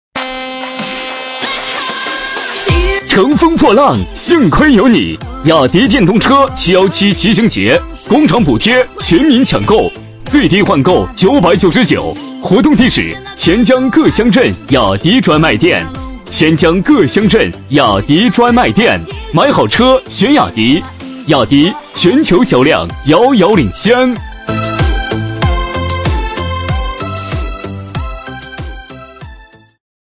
B类男10
【男10号促销】雅迪717骑行节
【男10号促销】雅迪717骑行节.mp3